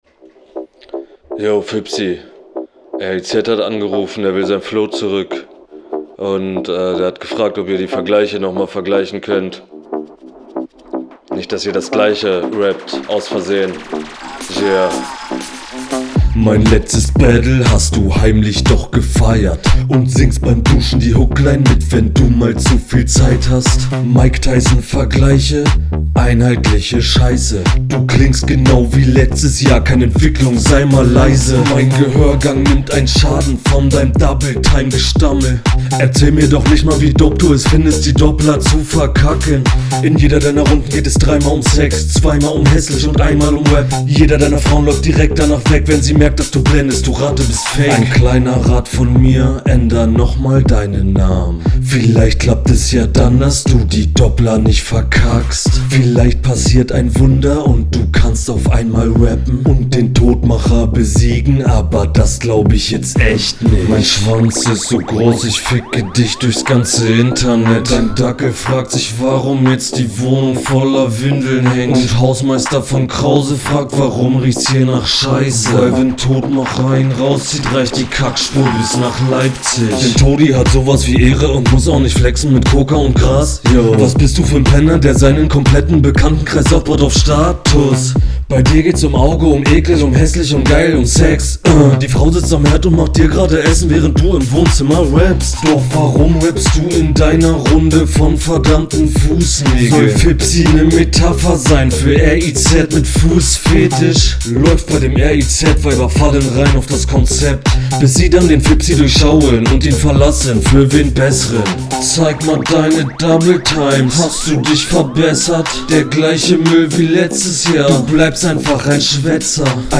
Mische oder Mikro nicht so geil. Aber flow geht fit und punches auch.
Flow: Die langsameren Stellen finde ich bisschen unangenehm zu hören.